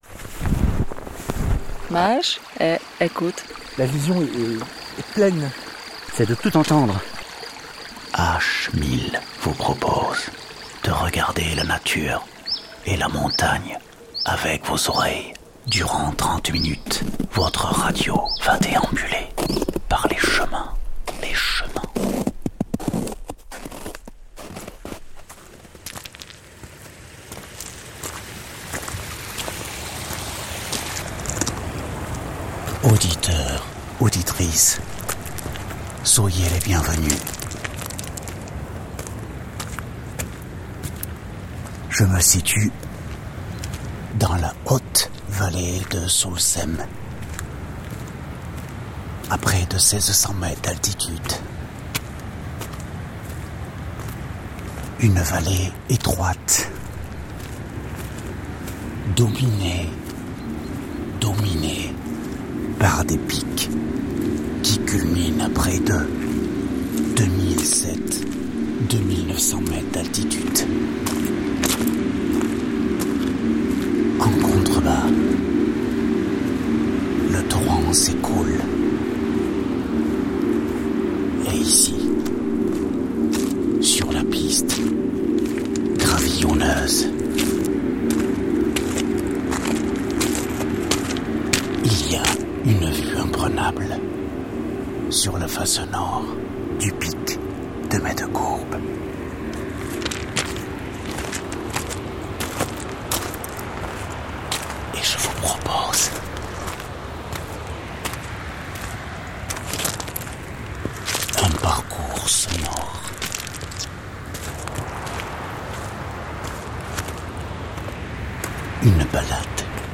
Plongez vous dans l'automne lors de cette carte postale auditive en montagne !